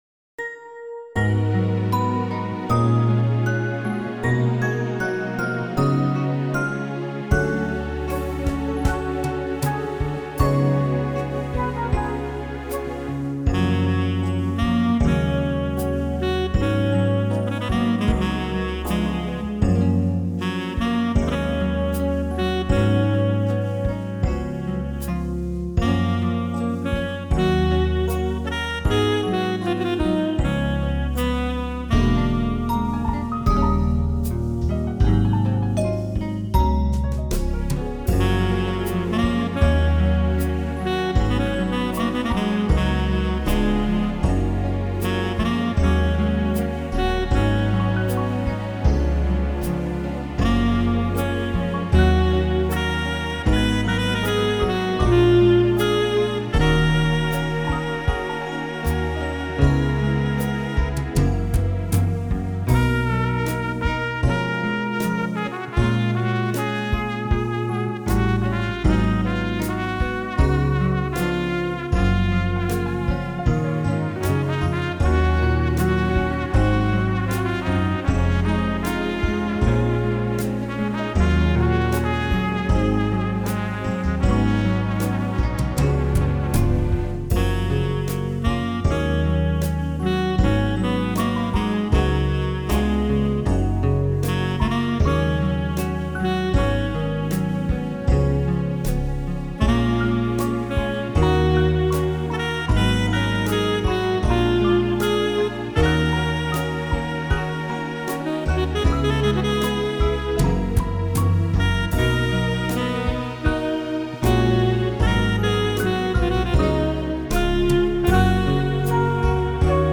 thpobhg7kl  Download Instrumental